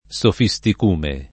[ S ofi S tik 2 me ]